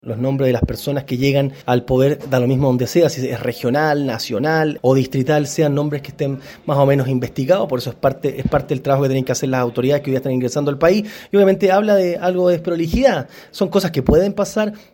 El diputado del Partido de la Gente, Javier Olivares, se sumó a las críticas de Flores, pero también pidió paciencia.